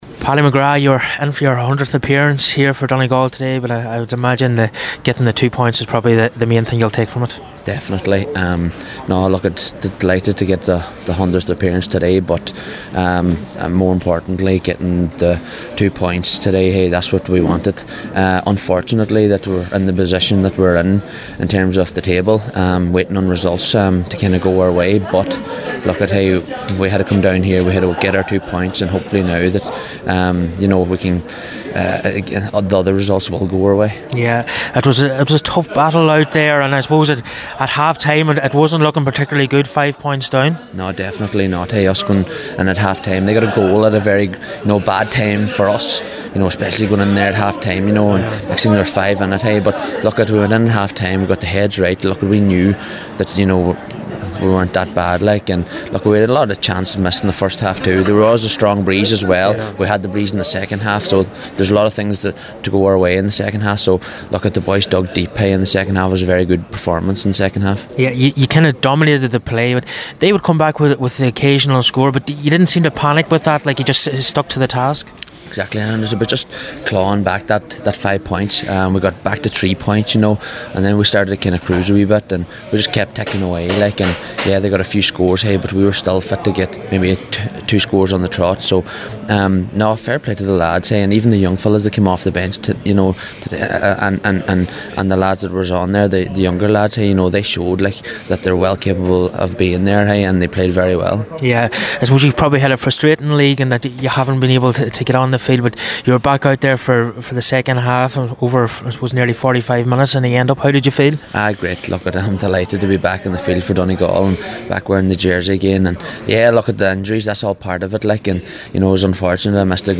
After the match
Donegal manager Declan Bonner